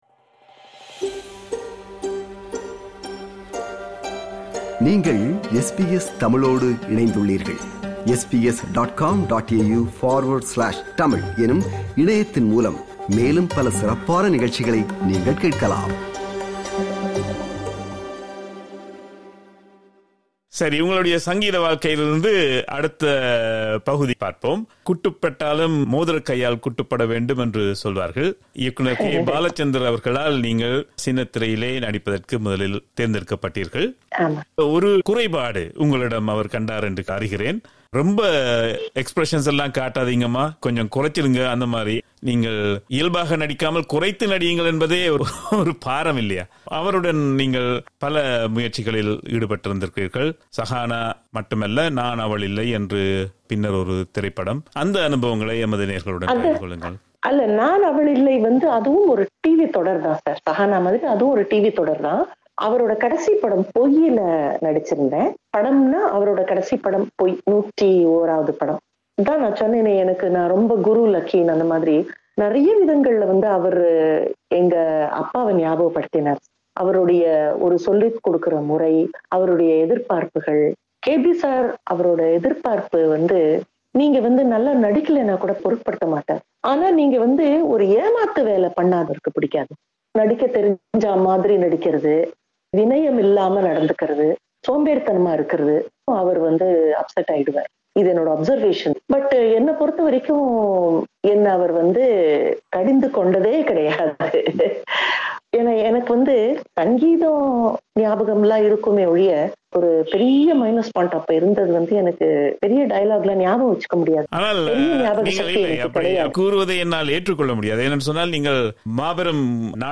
This is the concluding part of the two-part interview.